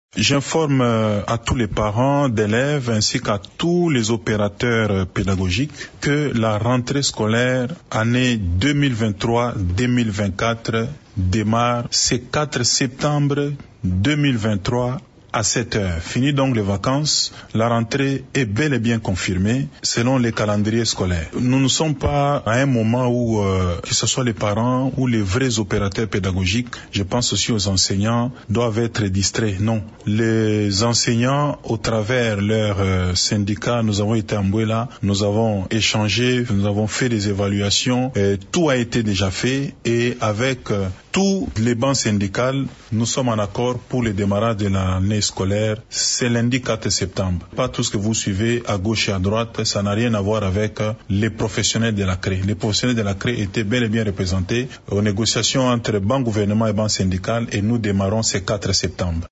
Dans un entretien accordé, samedi 2 septembre, à Radio Okapi, Tony Mwaba a insisté sur le fait que les enseignants sont d’accord pour reprendre le chemin de l’école lundi prochain :
Ecoutez le ministre de l’EPST :